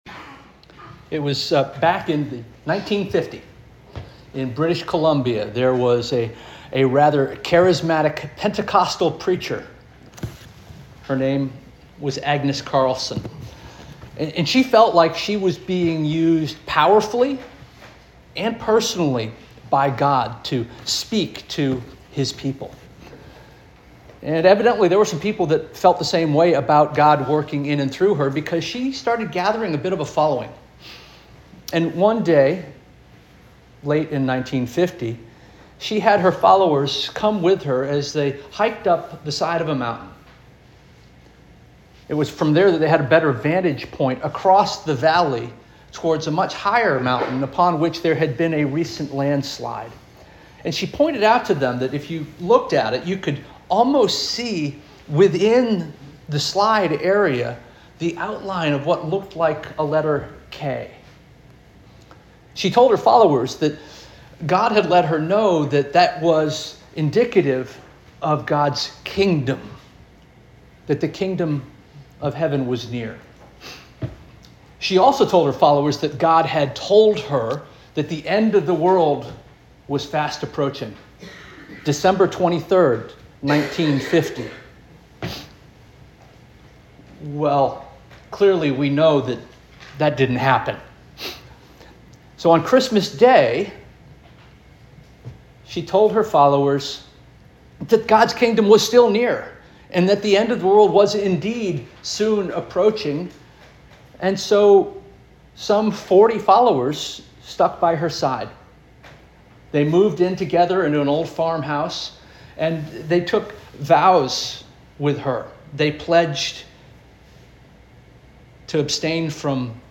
March 15 2026 Sermon - First Union African Baptist Church